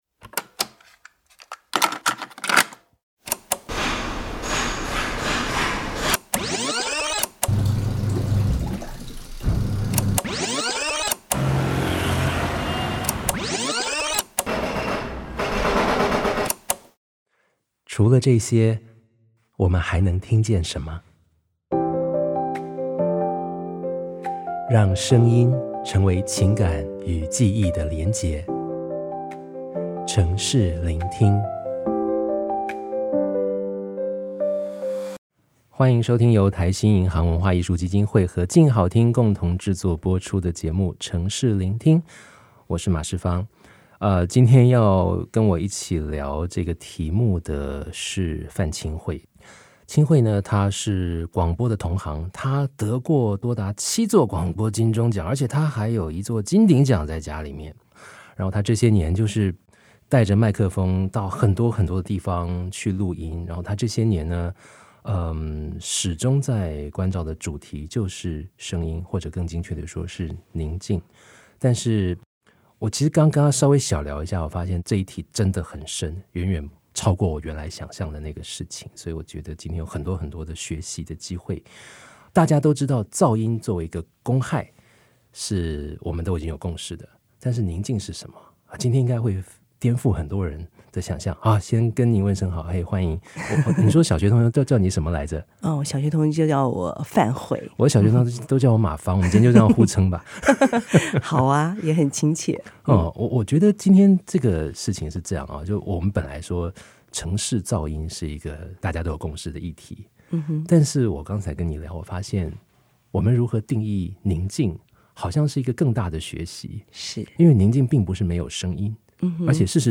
(05:38) 不只動物會絕種，聲音也會 (08:41) 先把世界從「背景音」救出來！有了覺察，聆聽才會長出行動 (09:26) 聲音試聽①：沿冰河遷徙、在台灣演化的中海拔特有種之聲 (15:50) 聲音試聽②：歐洲人的天籟 vs. 台灣人的魔神仔記憶 (21:56) 當震撼鼓聲響起，為何森林下起一場「蜜蜂屍體雨」？
(33:44) 聲音試聽③：誰說台北只有摩托車聲？陽明山的搖滾派對！